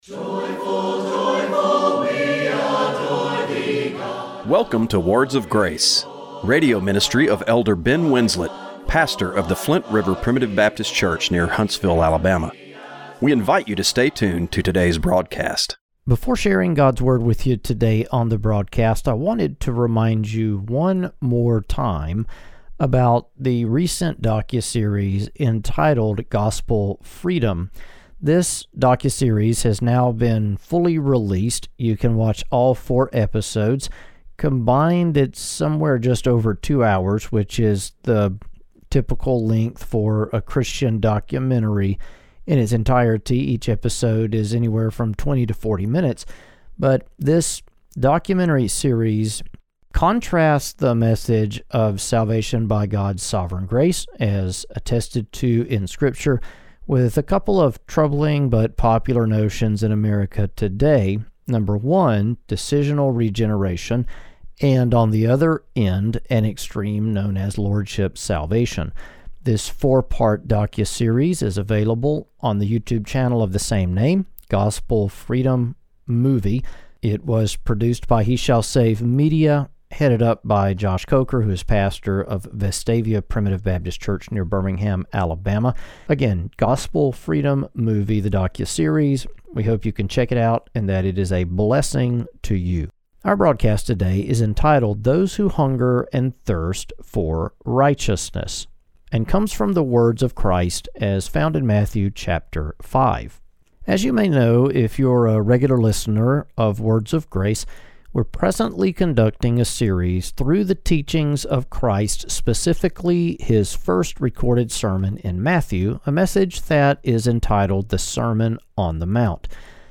Radio broadcast for February 23, 2025.